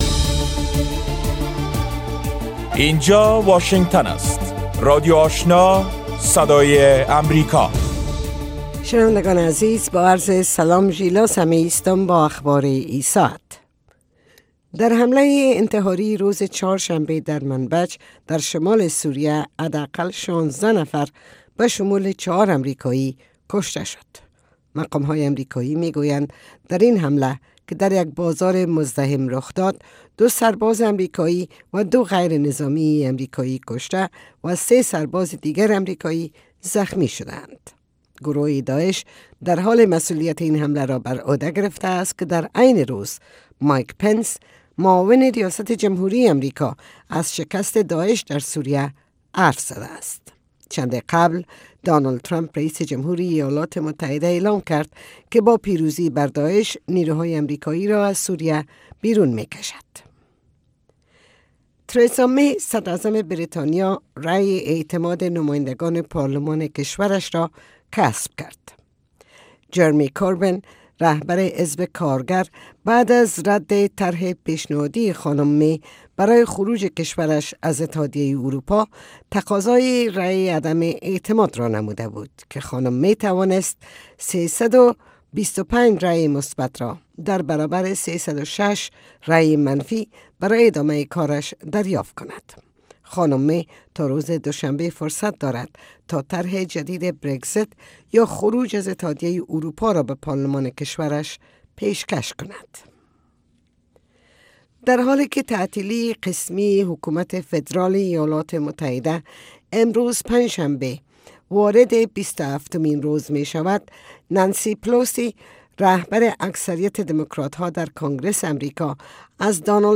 نخستین برنامه خبری صبح